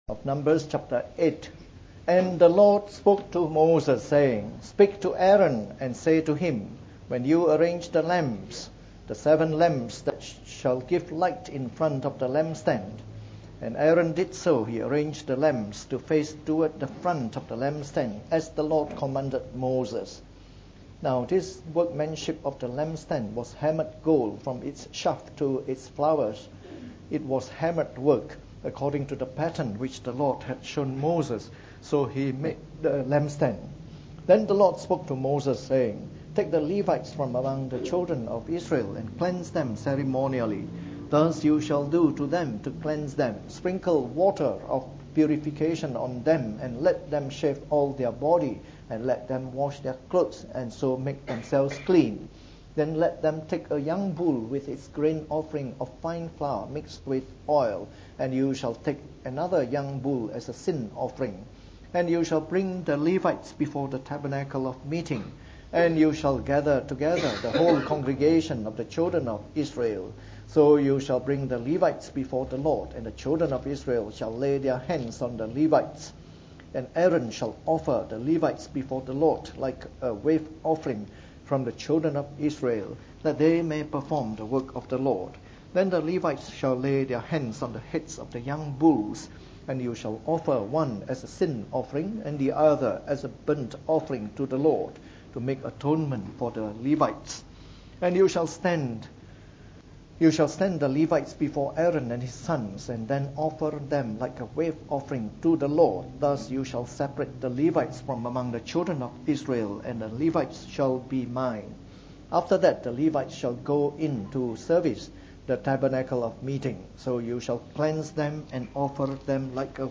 From our series on the “Book of Numbers” delivered in the Morning Service.